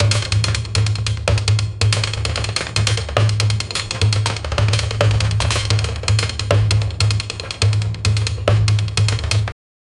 -sharpening-swords-elepha-mey4a6gn.wav